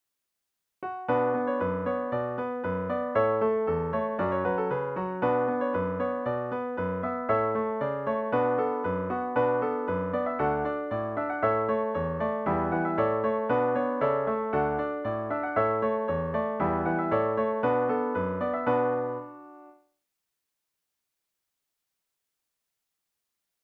DIGITAL SHEET MUSIC - PIANO ACCORDION SOLO